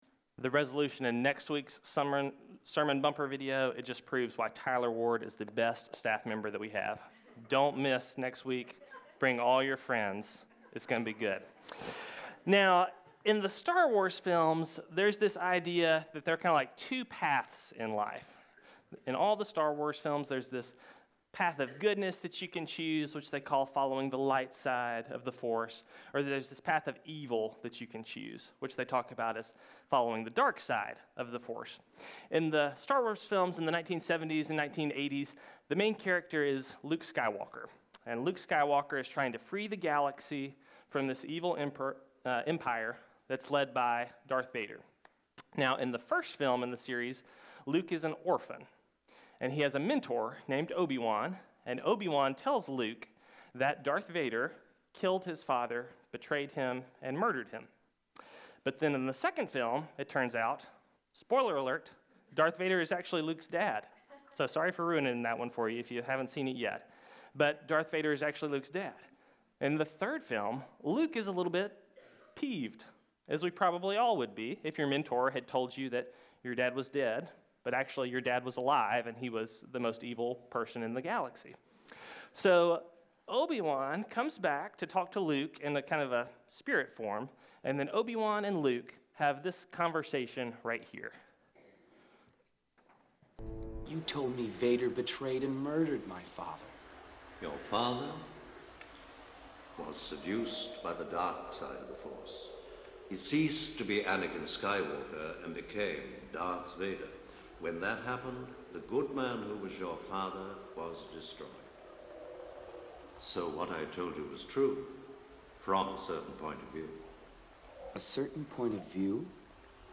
Sermons | Forest Hills Baptist Church